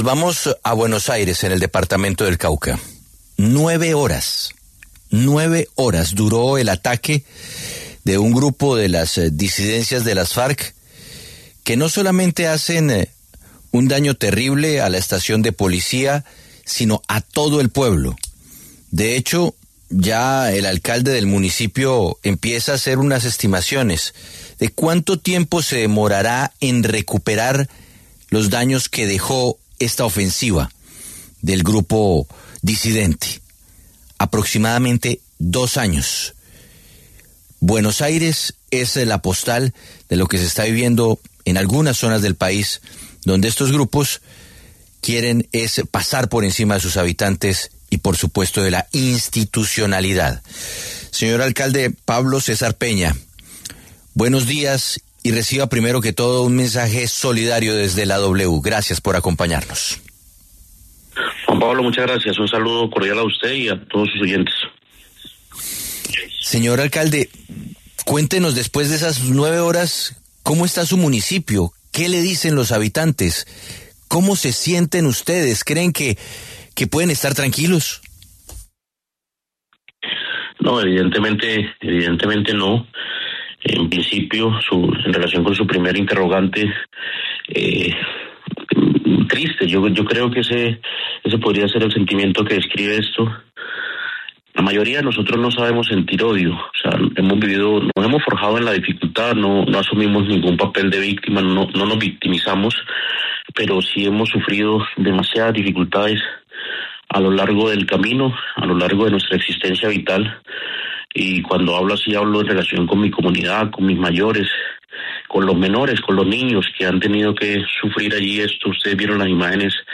El alcalde de Buenos Aires, Cauca, Pablo César Peña, en entrevista con La W, habló de la recuperación del municipio tras la devastadora incursión armada de las disidencias.